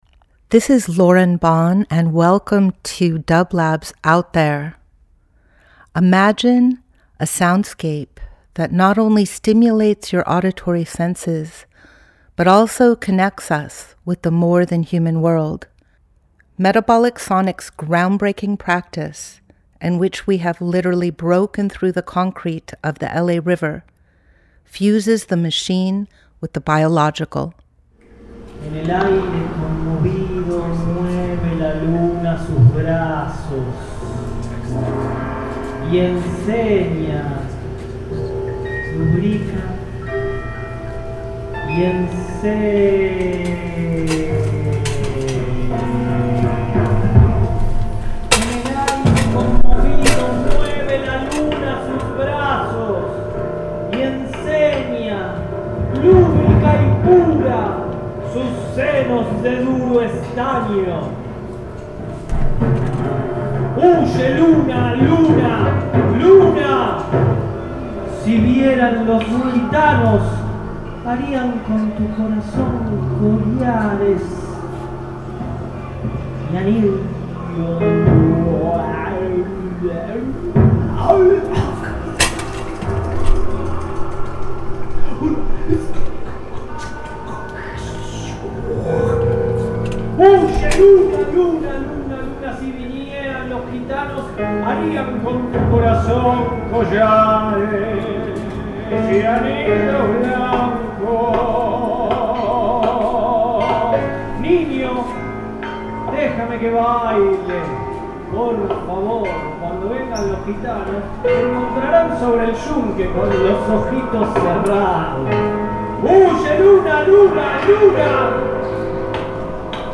Each week we present a long-form field recording that will transport you through the power of sound.
jamming on-line
Metabolic Studio Out There ~ a Field Recording Program 07.18.24 Experimental Field Recording Fourth World Future Roots Voyage with dublab into new worlds.